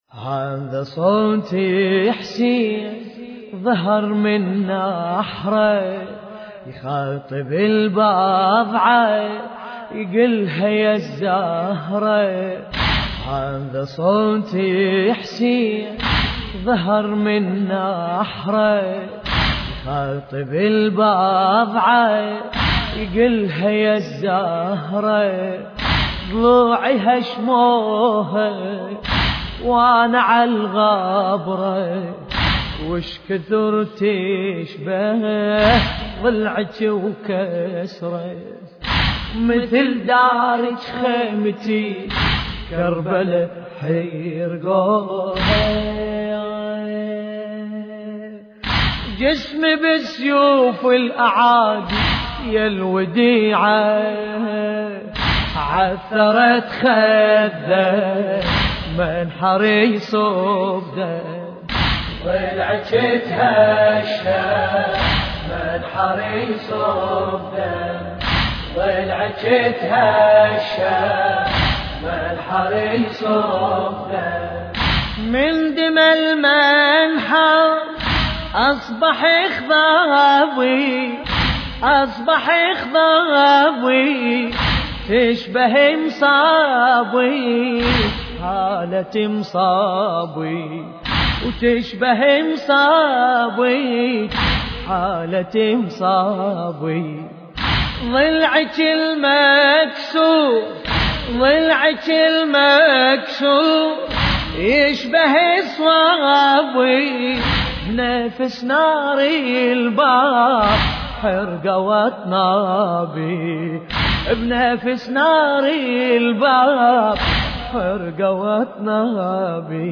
مراثي فاطمة الزهراء (س)